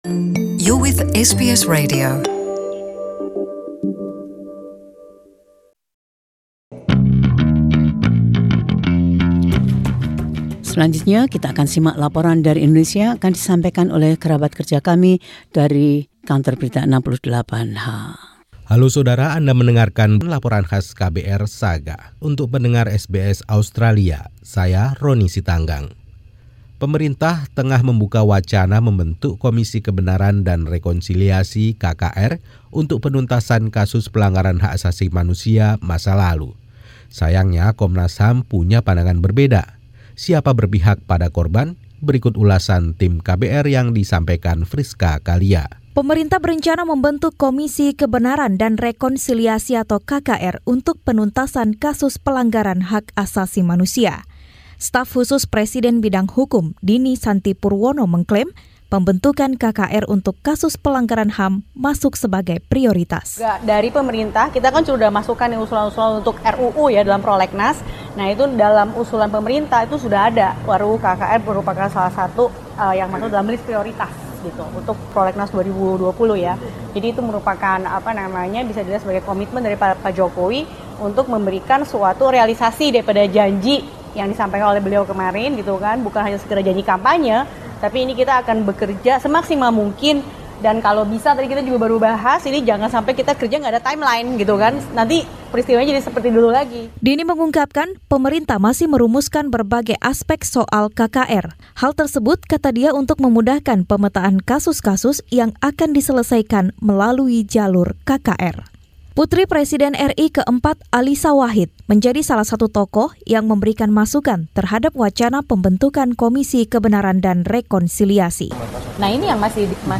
Laporan KBR 68H: Apakah Indonesia sebaiknya melakukan reformasi terhadap KKR ?